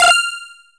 合计值计算成功音效.mp3